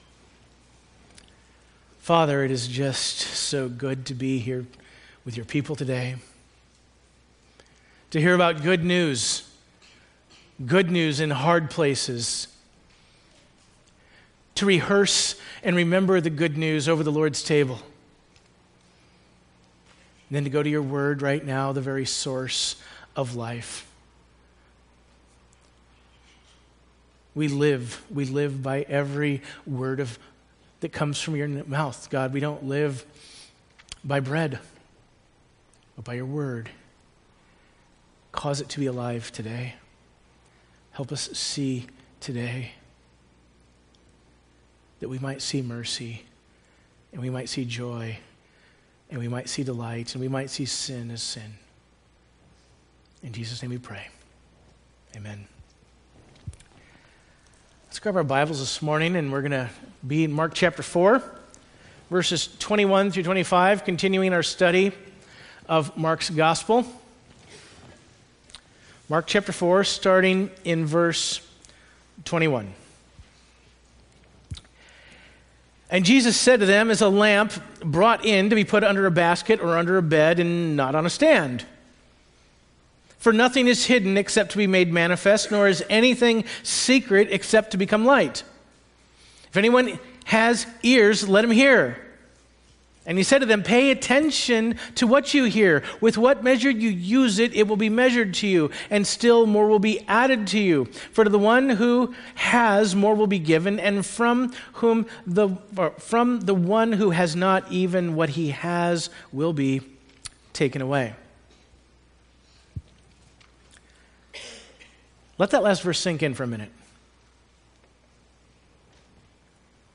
We apologize that there is no video available for this sermon.